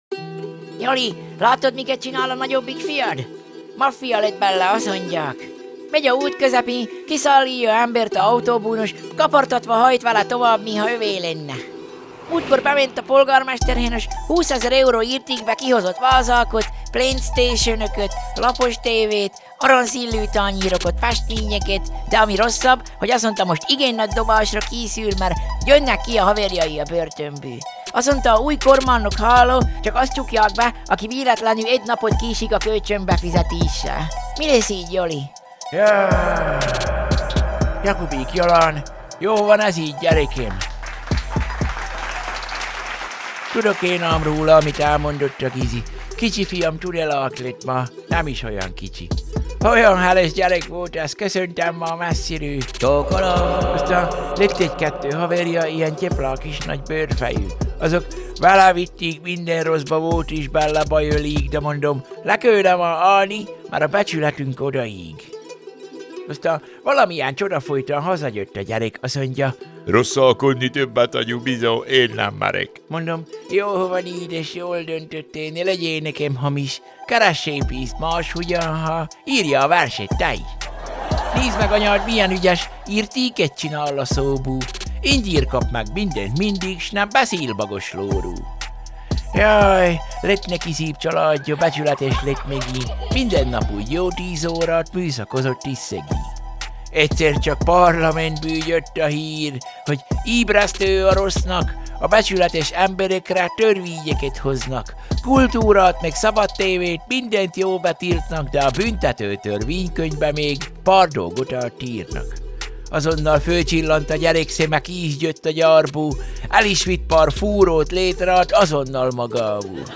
A mai internetes telekabaré komolyabb hangvételű epizódjában Jakubík Jolán erről a történetről mesél...